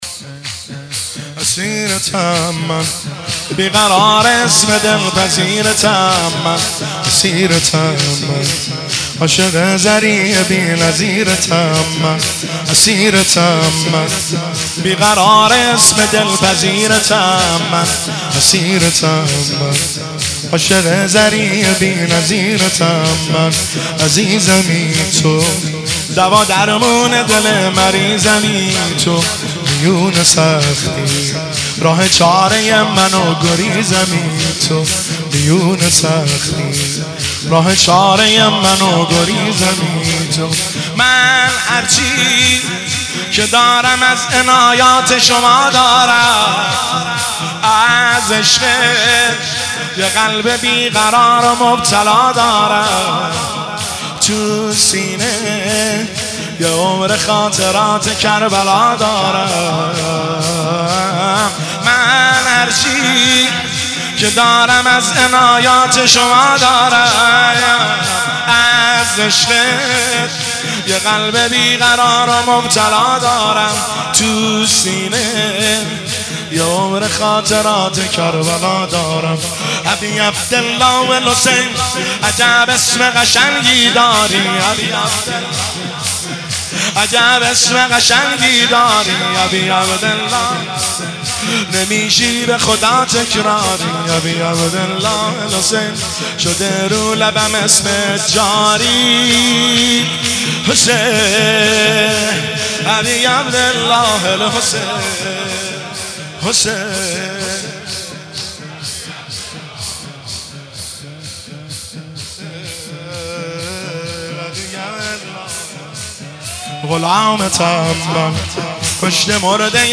اسیرتم من بیقرار اسم دلپذیرتم من - شور
شب چهارم محرم 1403